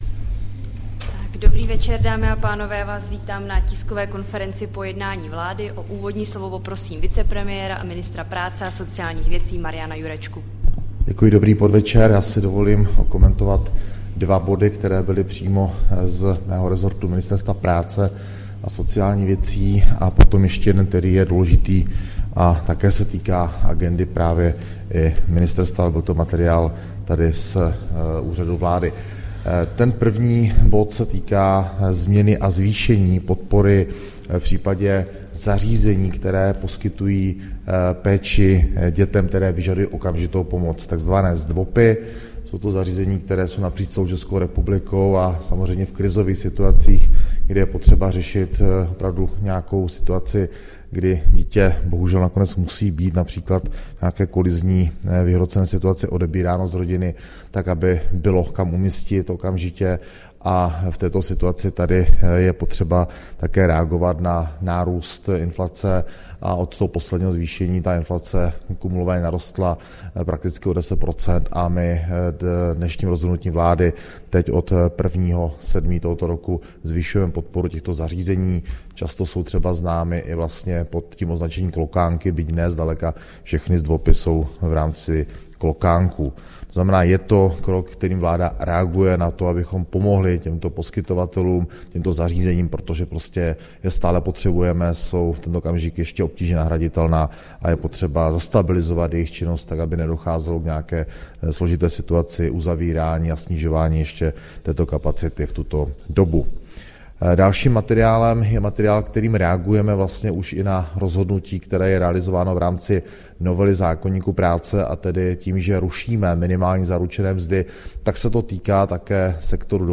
Tisková konference po jednání vlády, 26. června 2024